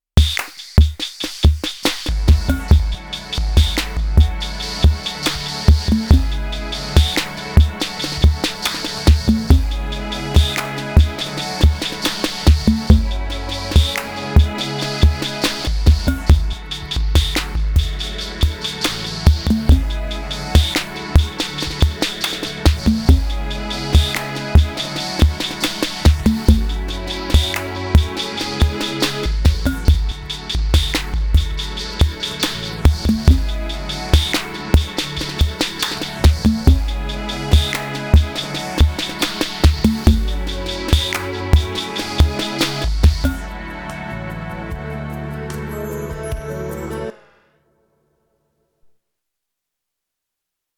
Auto Chord Progression